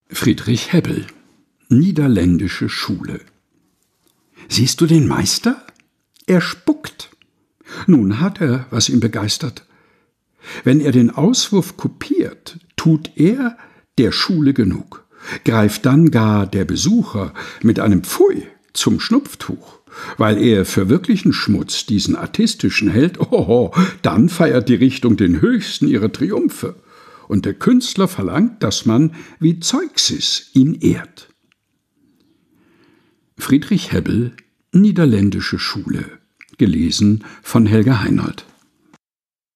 Texte zum Mutmachen und Nachdenken - vorgelesen